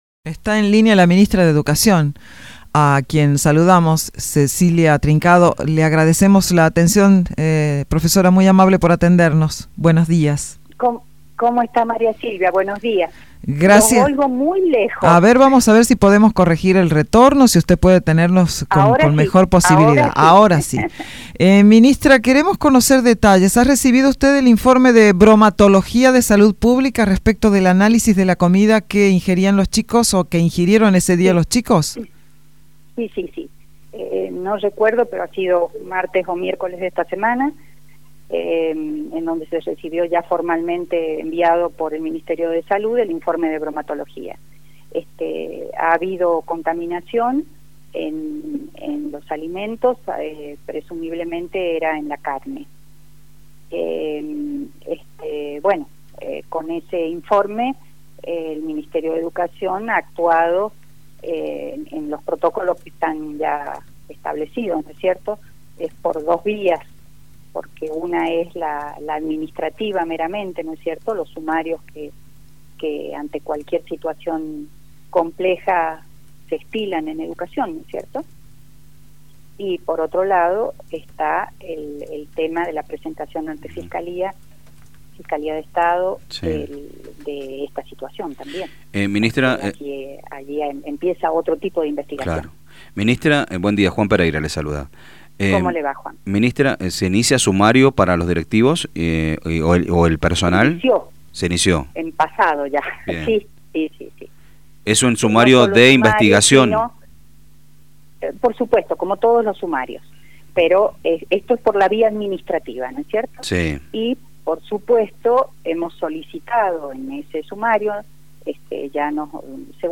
La ministra de educación, Cecilia Trincado, proporcionó detalles sobre los resultados de los estudios de Bromatología que arrojaron luz sobre la causa del incidente. Según Tincado, los análisis revelaron que la carne utilizada en la preparación de las comidas escolares estaba en estado de descomposición.